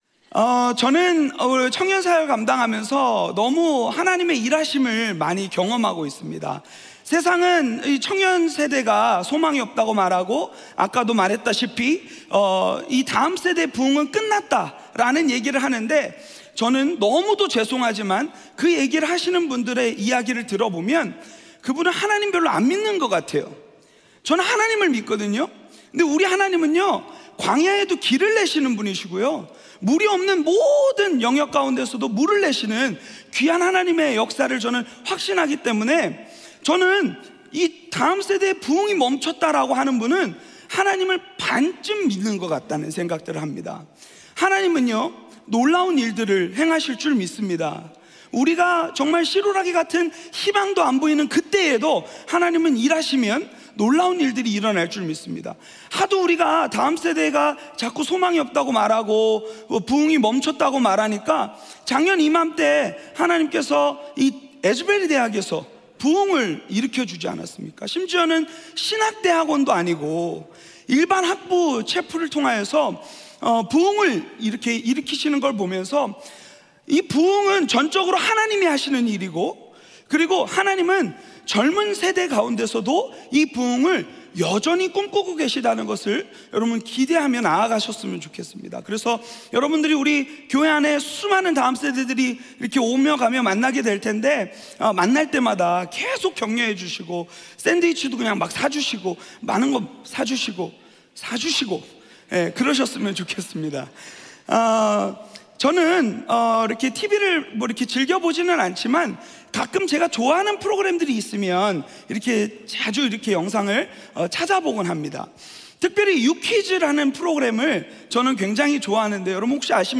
예배: 특별 집회